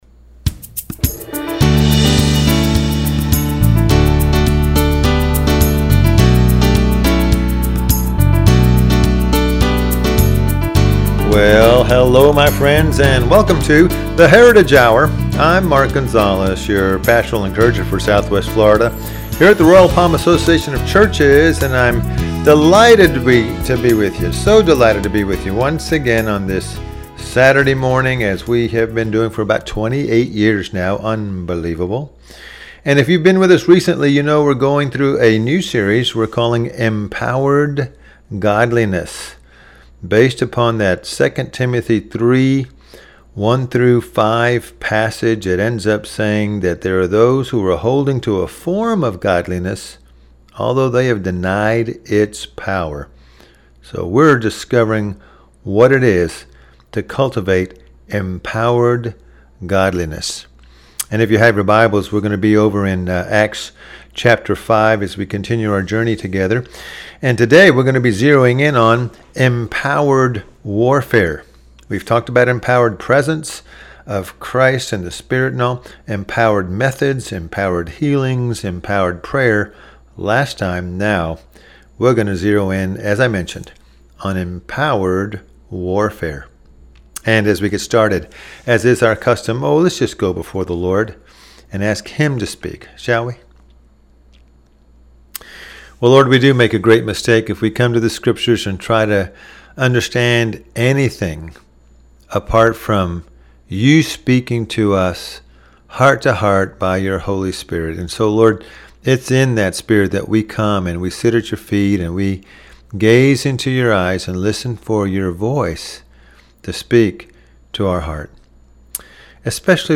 Service Type: Radio Message